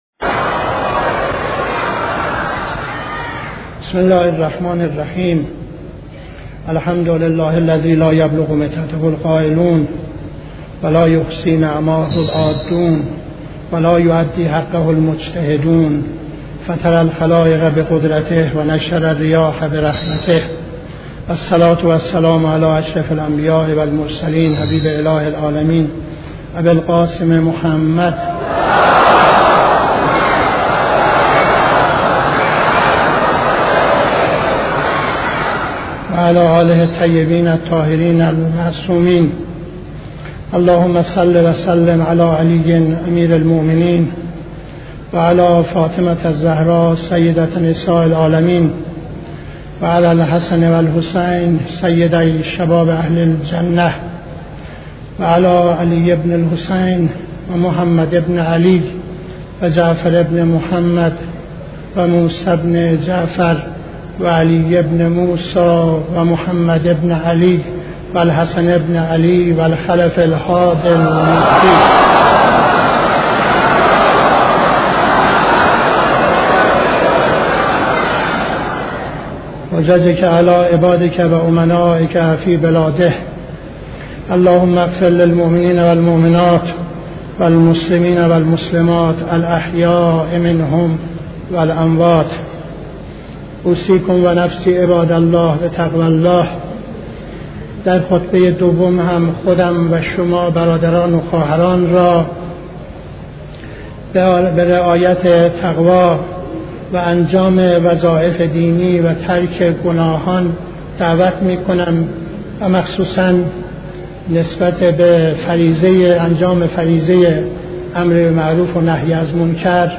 خطبه دوم نماز جمعه 07-12-71